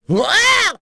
Lakrak-Vox_Attack3.wav